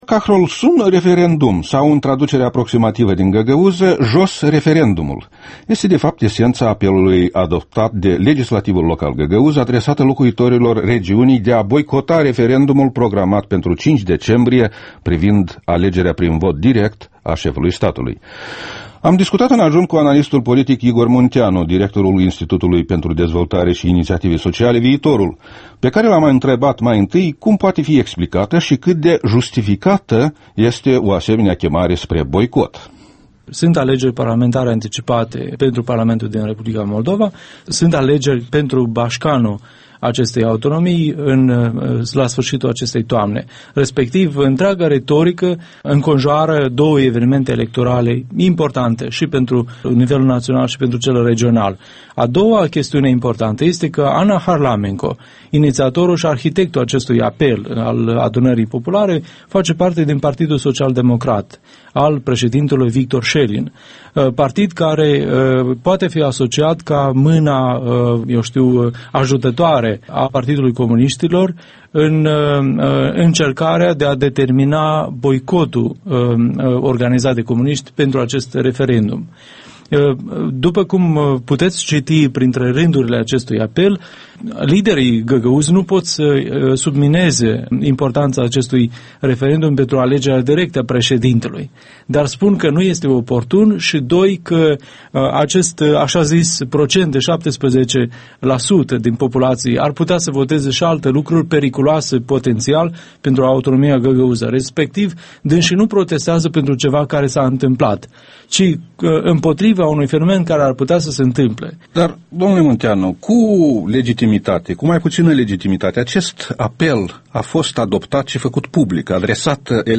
Interviul matinal EL: cu Igor Munteanu